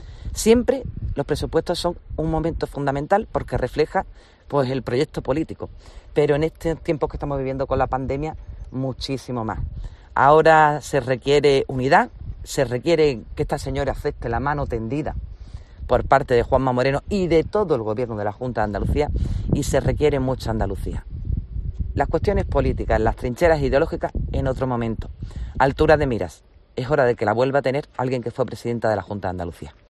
Loles López, secretaria general PP-A
En declaraciones a los periodistas en Aljaraque , ha indicado que considera que "ha llegado el momento de que el PSOE y Susana Díaz abandonen esa estrategia que llevan desde hace muchísimo tiempo de destrucción, de deterioro, de no aportar, de solo criticar y no arrimar el hombro, porque lo está arrimando toda la sociedad andaluza y lo está haciendo al completo el Gobierno de la Junta Andalucía y lo estamos haciendo otros partidos políticos".